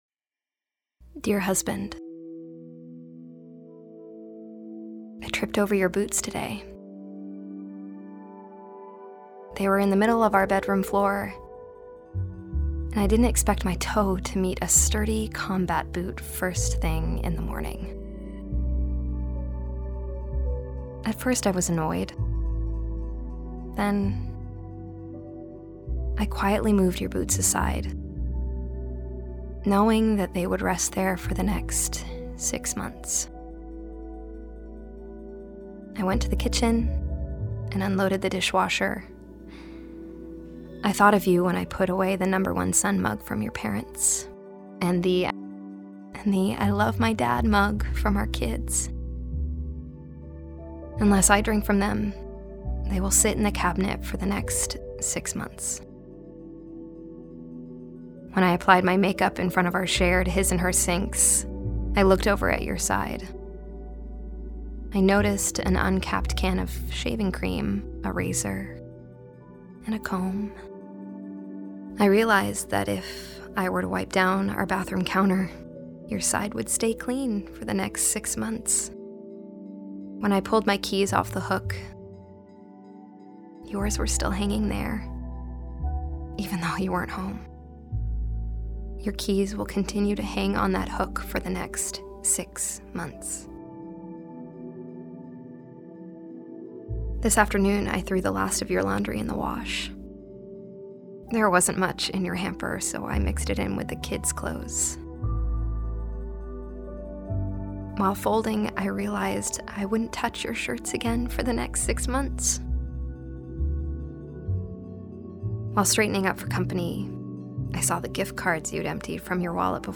Female Voice Over, Dan Wachs Talent Agency.
Warm, Genuine, Conversational.
Dramatic